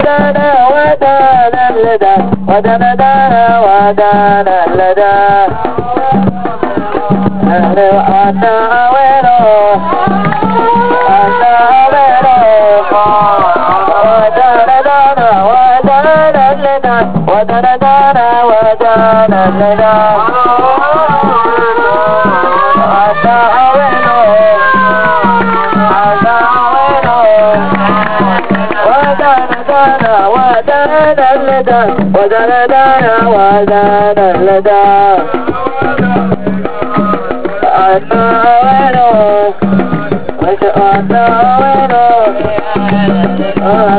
تفضلو يا اخوان اهازيج للنادي الاهلي:
ثانيا اهازيج الجمهور ,قم بحفظها (save traget as):